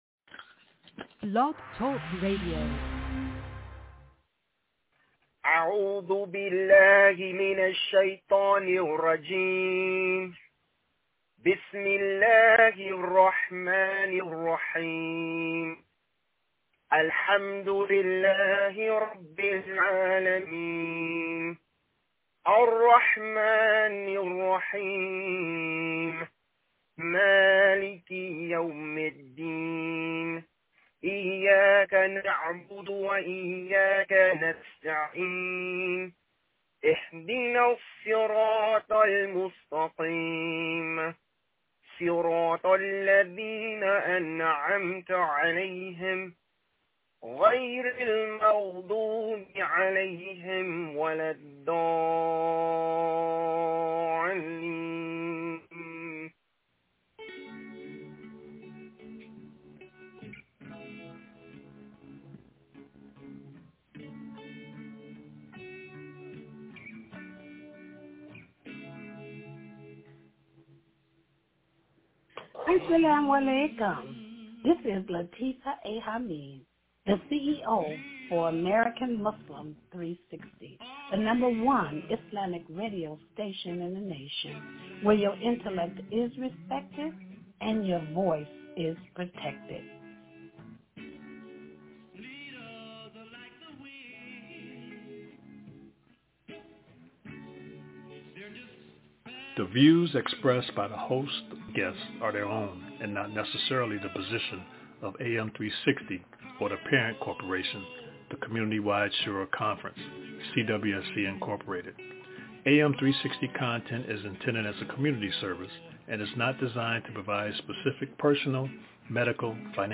1 Recitation of the 18th sura of Quran entitled Kahf
recitation-of-the-18th-sura-of-quran-entitled-kahf.mp3